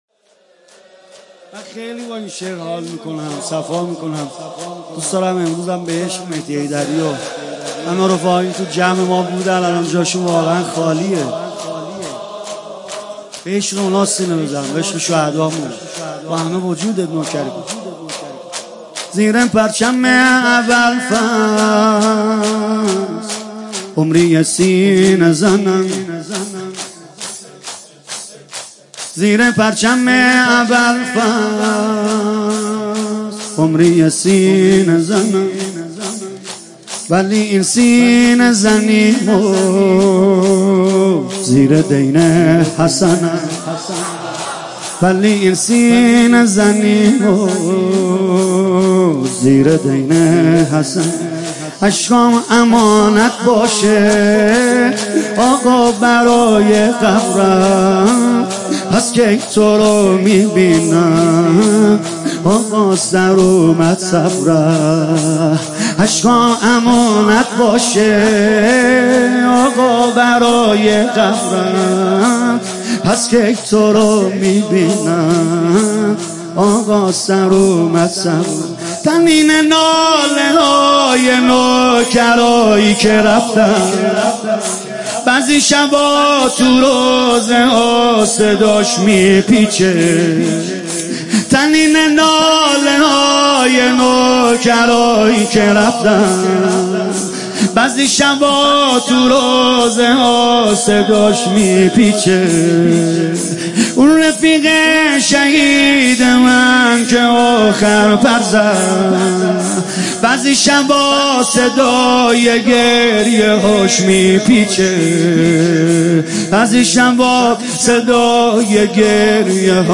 مداحی
مراسم هفتگی